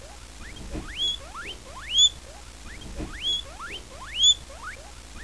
Mowa Świnek Morskich
PISK -jest głosem zwierzęcia bezpośrednio zagrożonego, np. gryzionego przez innego osobnika. Zwierzę odzywa się też tak, gdy właściciel sprawia jej ból, lub jeśli jest przestraszona.
pisk.wav